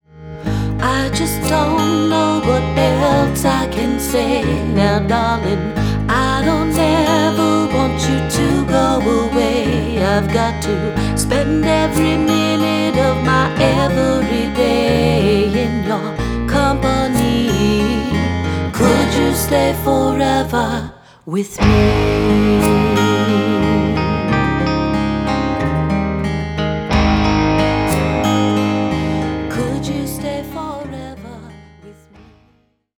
Vocals
Guitar, bass, and percussion